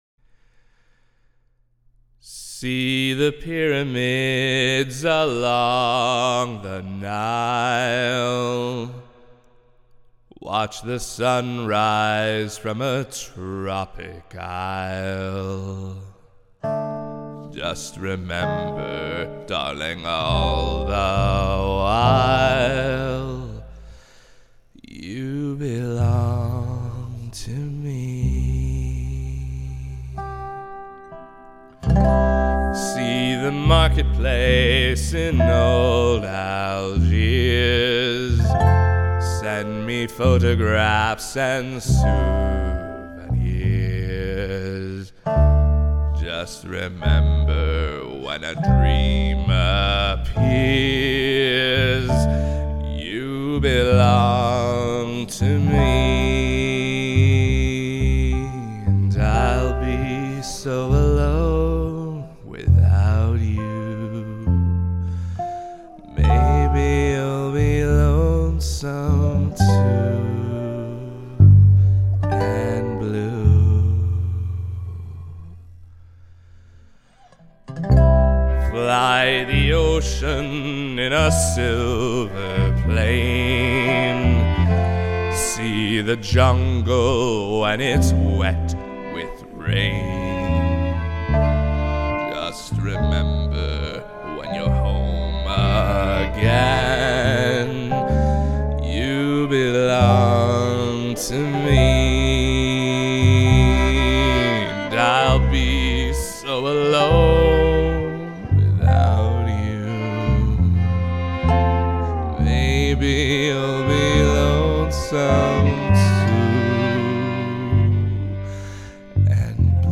Me singing a 1950's slow jazz tune.